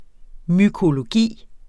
Udtale [ mykoloˈgiˀ ]